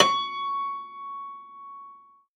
53v-pno11-C4.wav